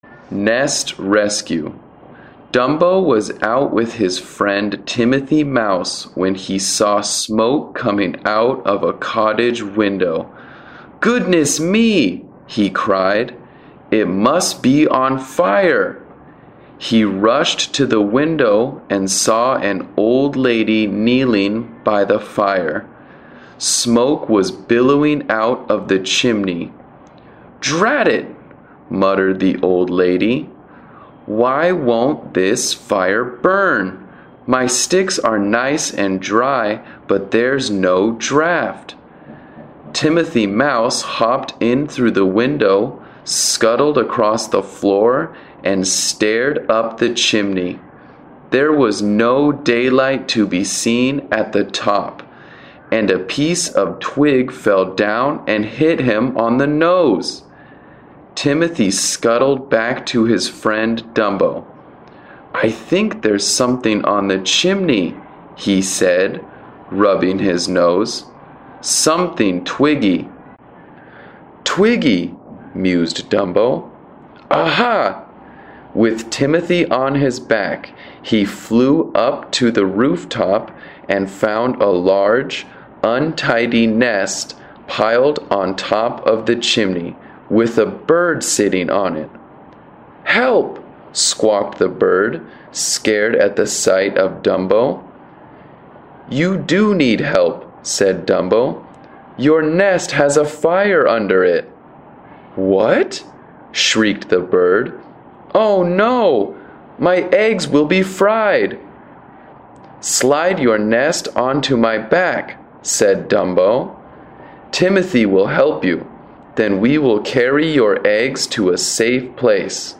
桃園市朗讀第一篇Nest Rescue.mp3